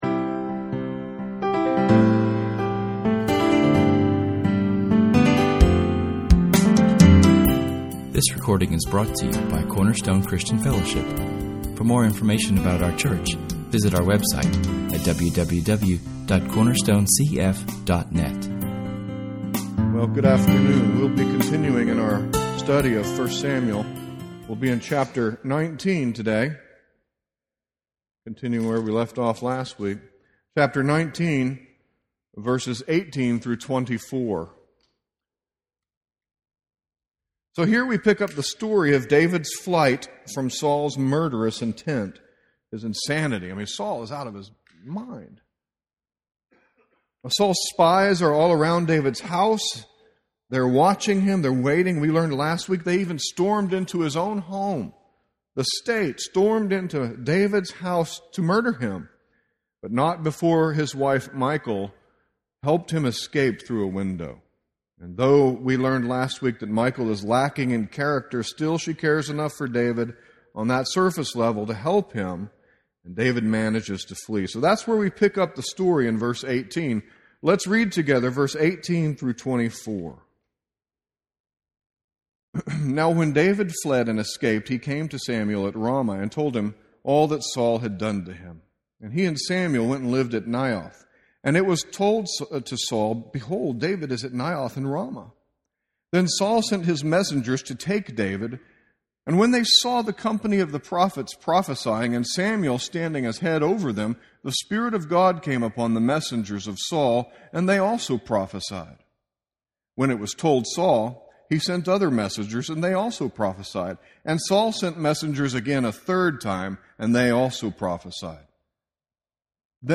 Public reading: [esvignore]Psalm 2[/esvignore] 1 Samuel 19:18-24 Psalm 2